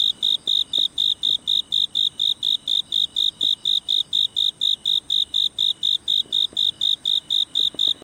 ツヅレサセコオロギ
この様子と途切れずなき続けることで判断しました。